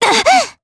Xerah-Vox_Damage_jp_03.wav